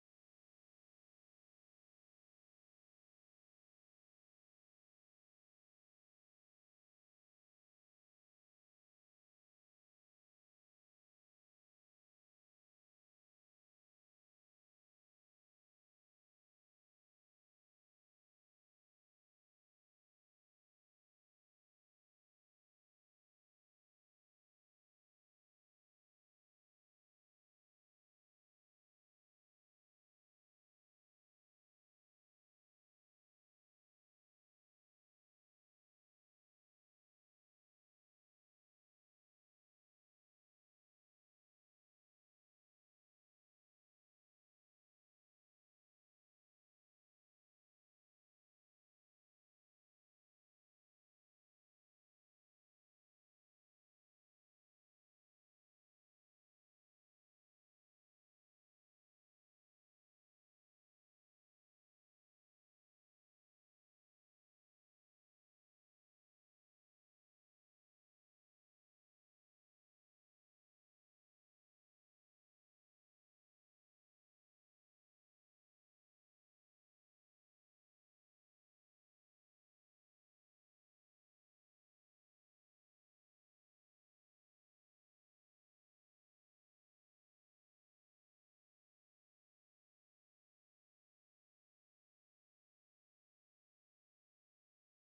من محاضرة : ( حق الراعي والرعية ) .